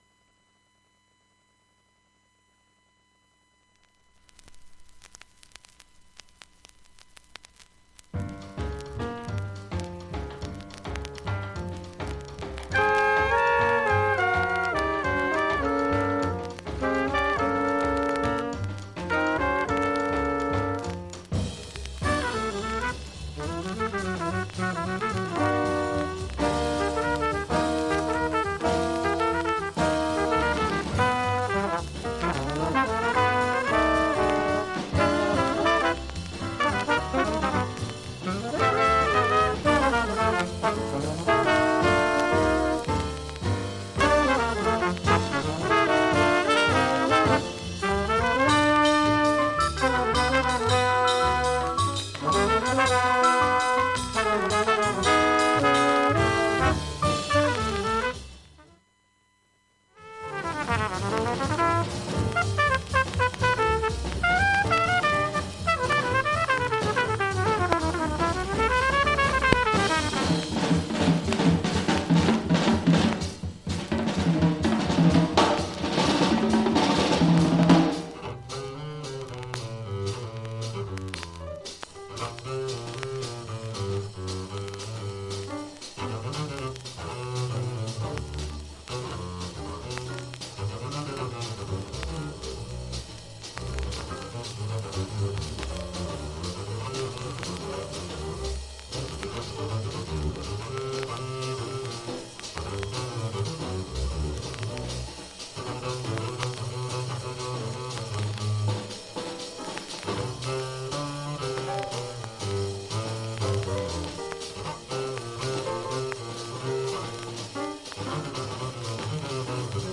全般的にバックチリ、プツなどありますが、
A-2に特に数本の縦スレでチリパチ出ますが、
音自体は小さい感じです。音圧もあり、
現物の試聴（上記A-2ダイジェスト3分）できます。音質目安にどうぞ
青大 深溝 MONO PAT#スタンパー